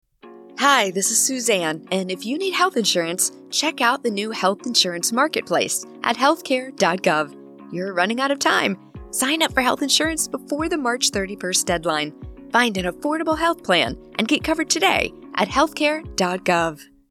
Radio Spot Relatable, Mother, Friendly
General American
Middle Aged
DEMO--Radio Spot  Relatable Personable W Music.mp3